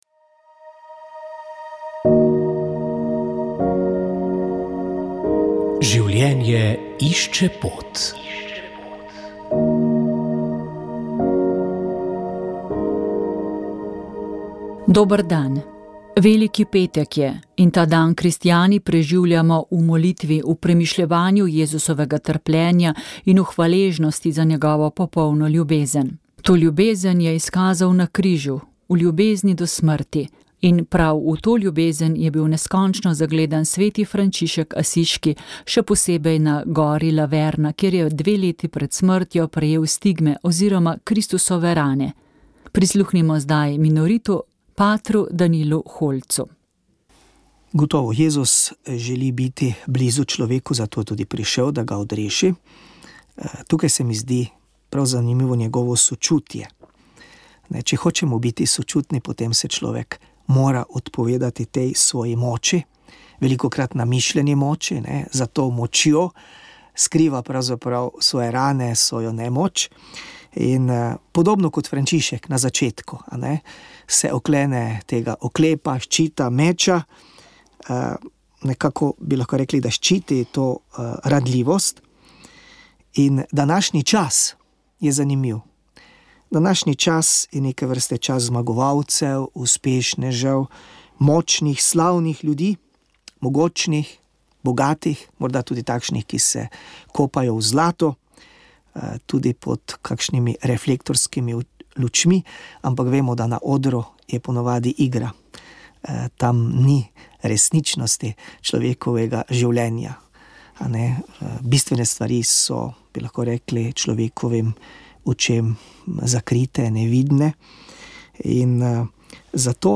Oddaja Moja zgodba je nastala ob posnetku pogovora.